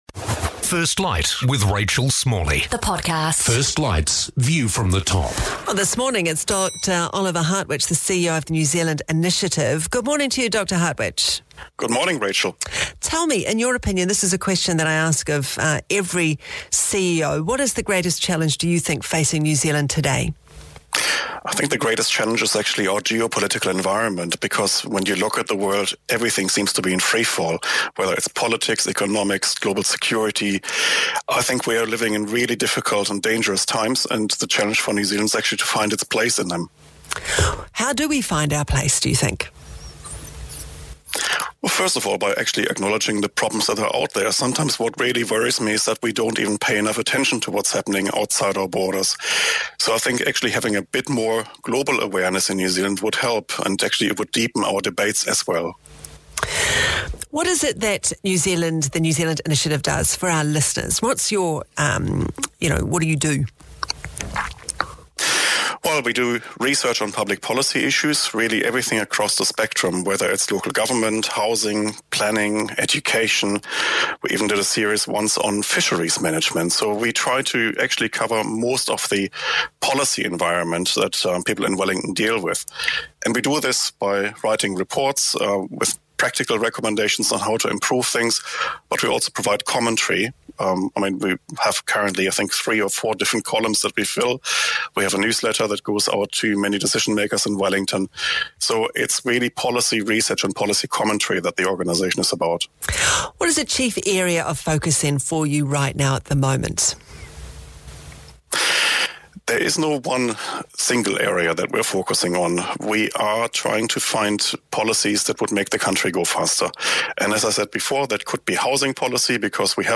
Today FM - First Light - Rachel Smalley